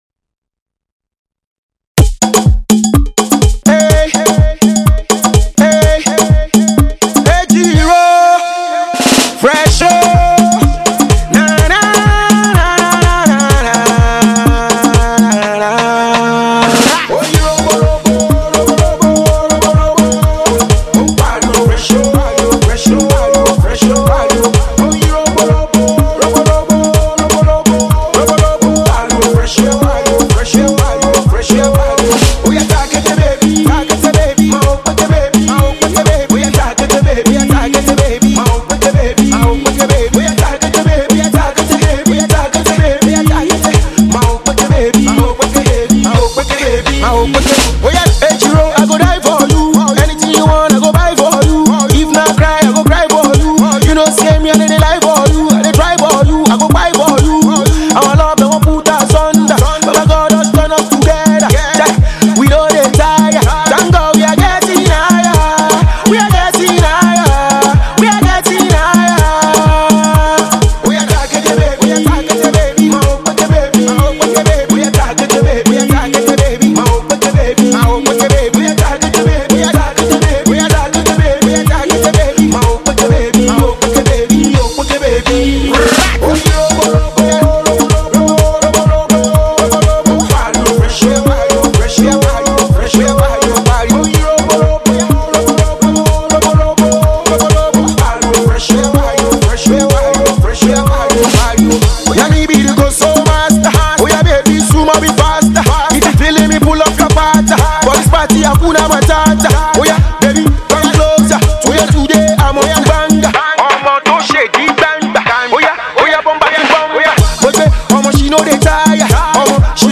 RnB crooner